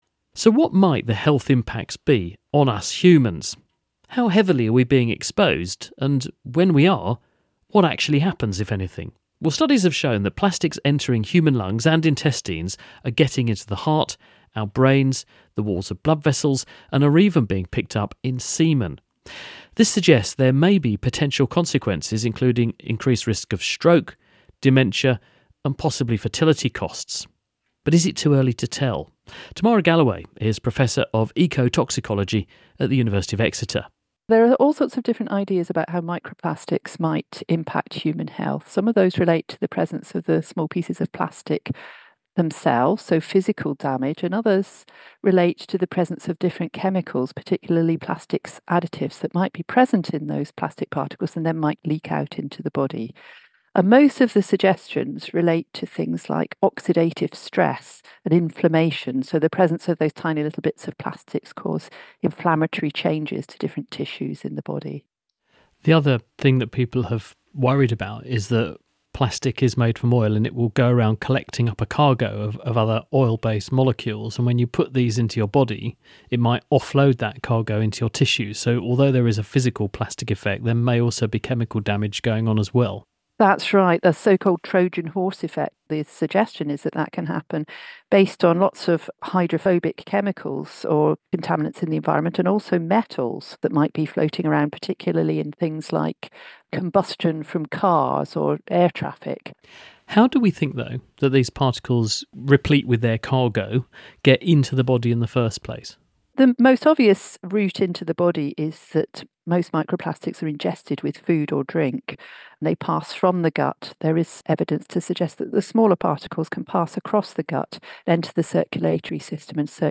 Interviews with Scientists
Interviews about medicine, science, technology and engineering with scientists and researchers internationally...